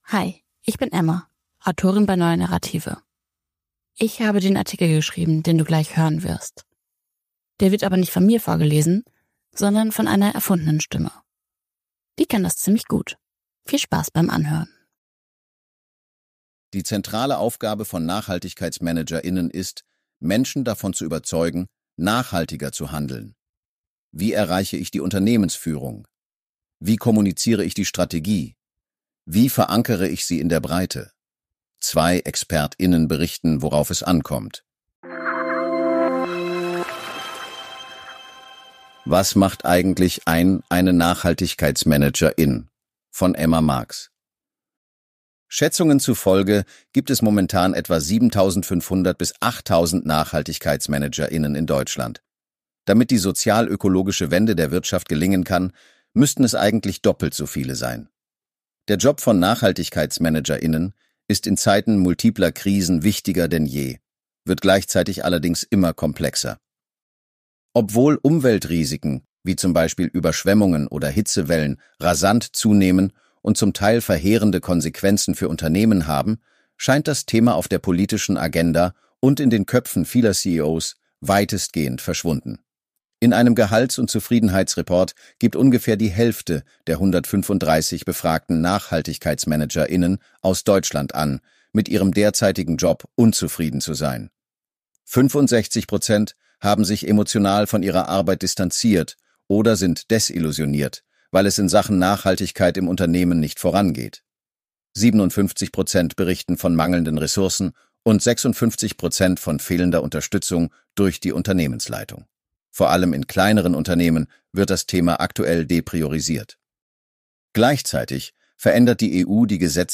Zwei Expert*innen berichten, worauf es ankommt.